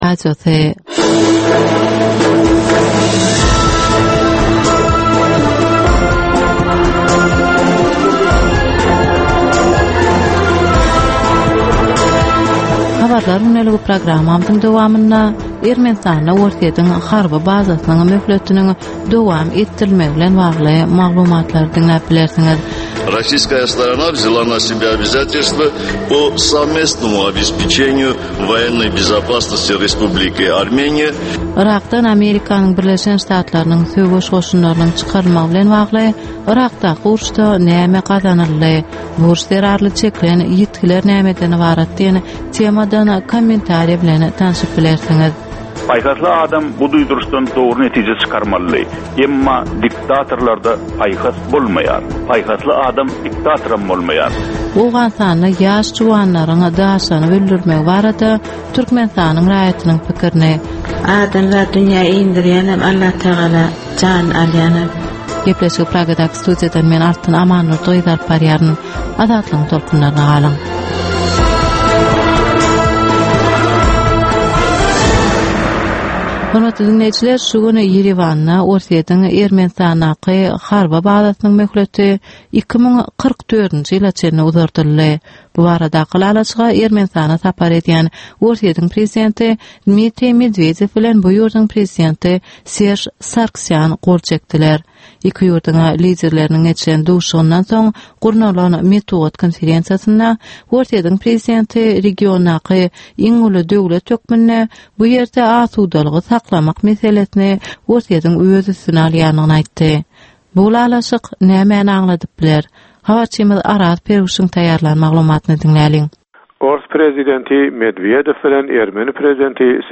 Türkmenistandaky we halkara arenasyndaky soňky möhüm wakalar we meseleler barada ýörite informasion-analitiki programma. Bu programmada soňky möhüm wakalar we meseleler barada giňişleýin maglumatlar, analizler, synlar, söhbetdeşlikler, kommentariýalar we diskussiýalar berilýär.